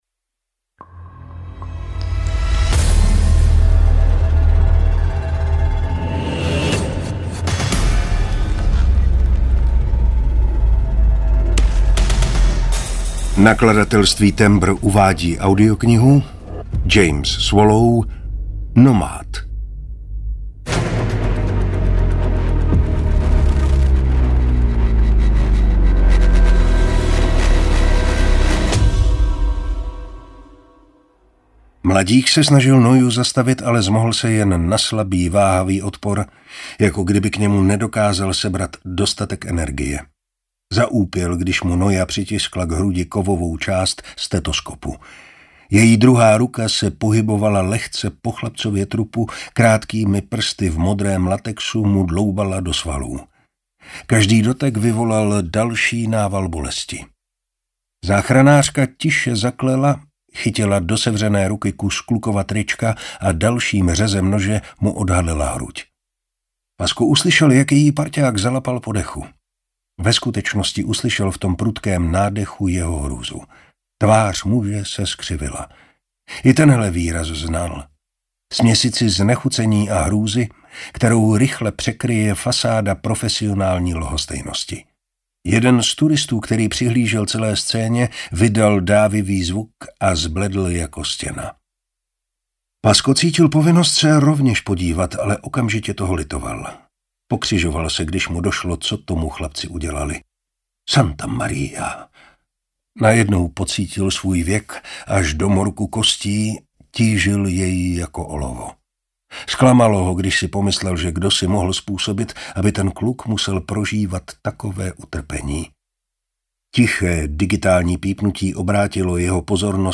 Nomád audiokniha
Ukázka z knihy
• InterpretMartin Preiss